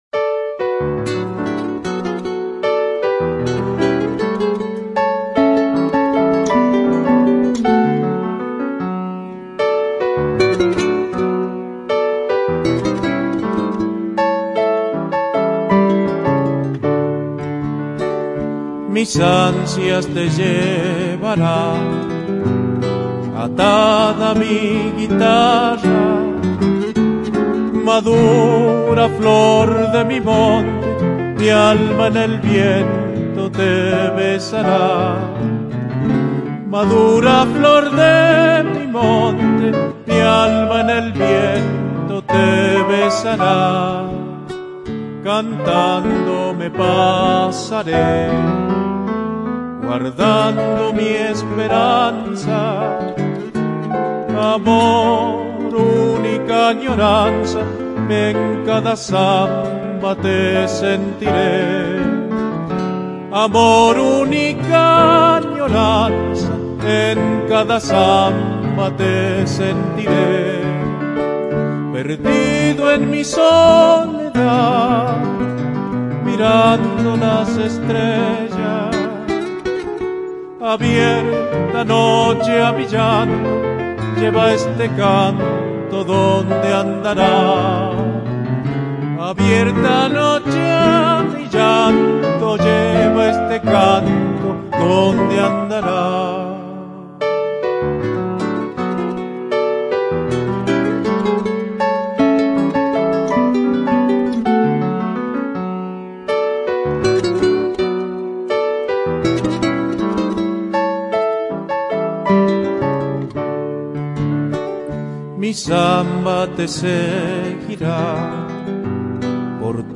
voz y guitarra
piano